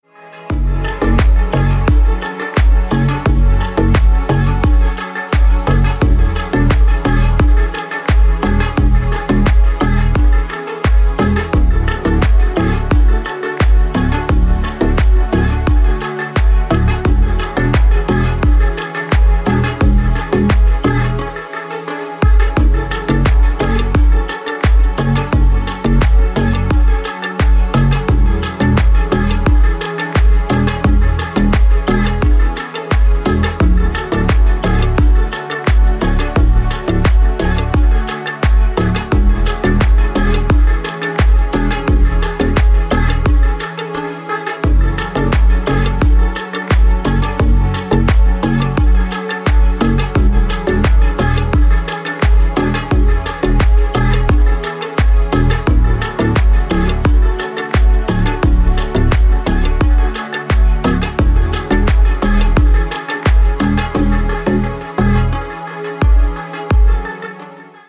Лайтовый рингтон на телефон
• Категория: Красивые мелодии и рингтоны